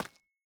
Minecraft Version Minecraft Version 21w07a Latest Release | Latest Snapshot 21w07a / assets / minecraft / sounds / block / calcite / step3.ogg Compare With Compare With Latest Release | Latest Snapshot
step3.ogg